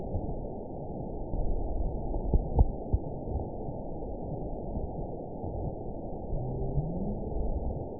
event 920561 date 03/30/24 time 03:33:26 GMT (1 year, 1 month ago) score 9.18 location TSS-AB04 detected by nrw target species NRW annotations +NRW Spectrogram: Frequency (kHz) vs. Time (s) audio not available .wav